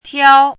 chinese-voice - 汉字语音库
tiao1.mp3